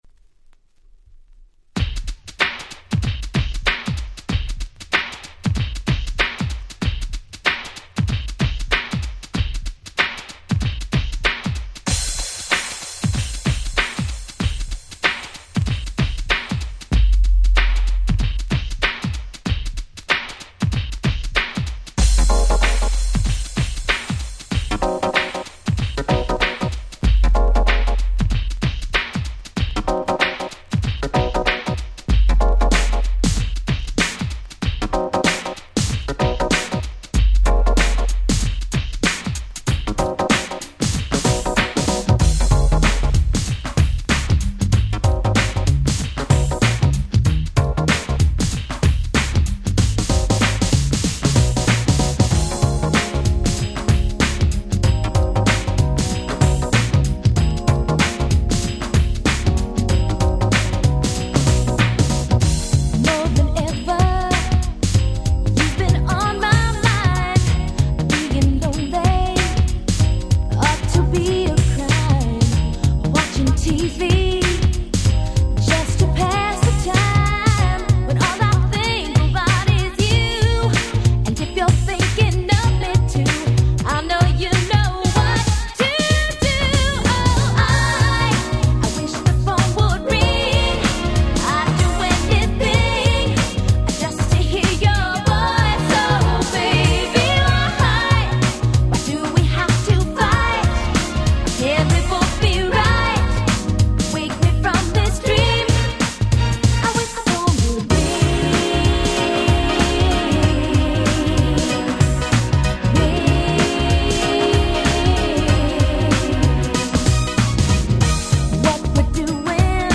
92' Smash Hit R&B !!
スタンダードなGround Beatトラックに切ない美メロな歌が載る超良い曲！！